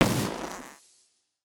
BombExplode.wav